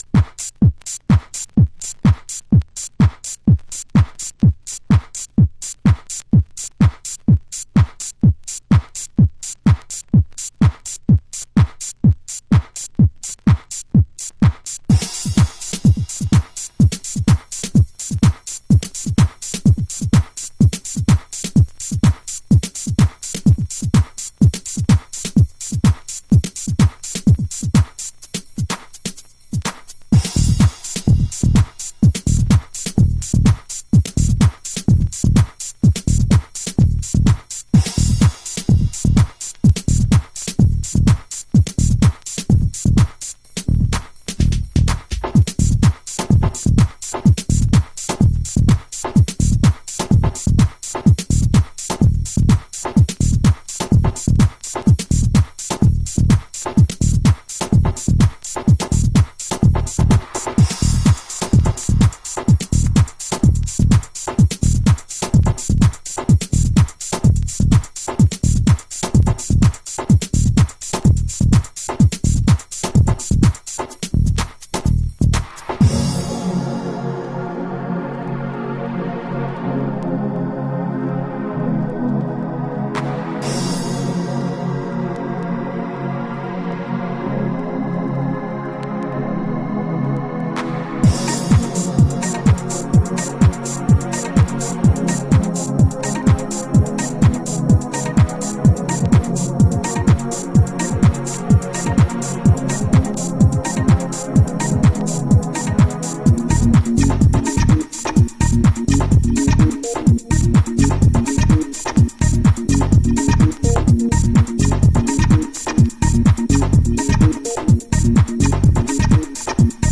視聴1分16秒あたりのブレイクで一気に引き込まれます。